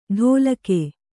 ♪ ḍhōlake